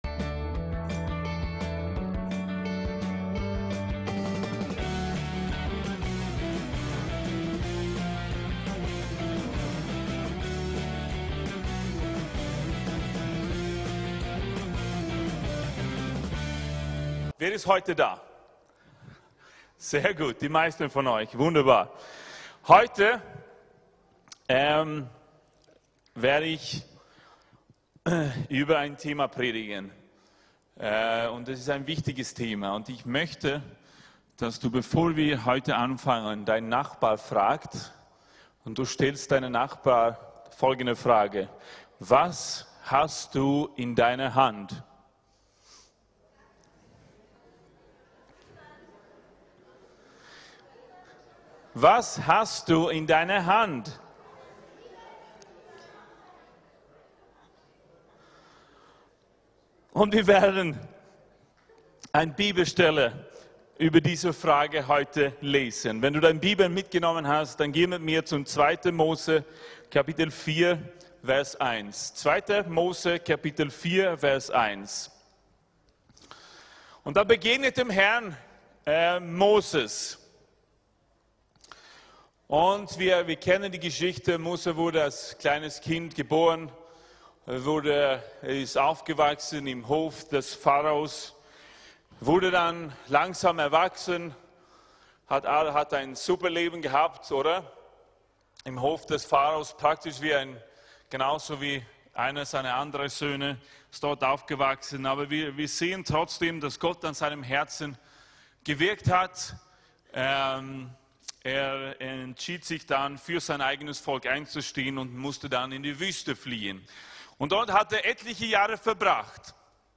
VCC JesusZentrum Gottesdienste (audio) Podcast